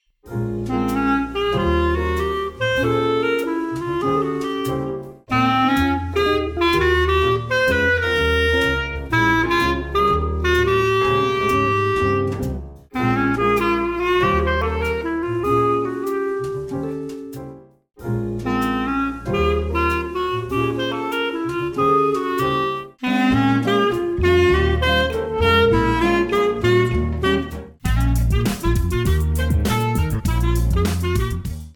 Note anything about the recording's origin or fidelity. By the way, I recorded all of these tracks without charts.